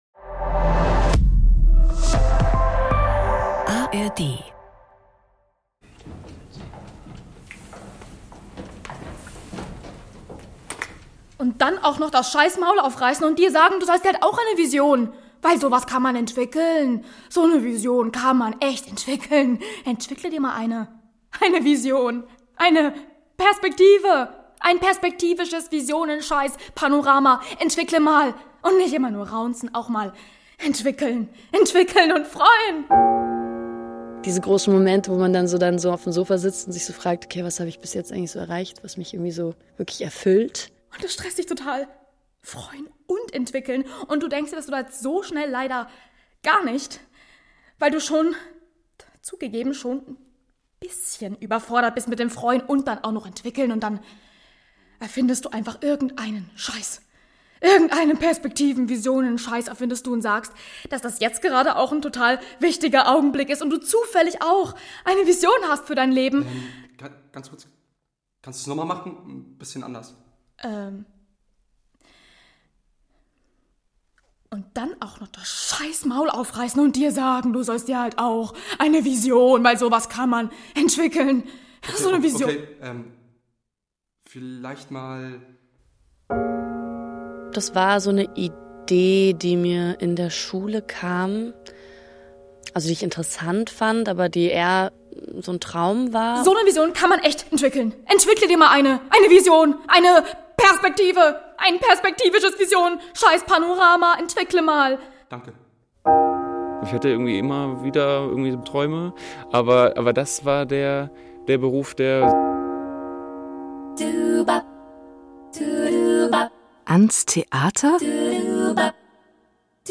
Das Feature befragt dazu Schauspielstudierende der Hochschule für Musik, Theater und Medien Hannover. Neun junge Frauen und Männer, die am Ende ihres herausfordernden Studiums vor dem erhofften Berufseinstieg stehen und dafür an ihrem ganz eigenen Vorsprechrepertoire arbeiten.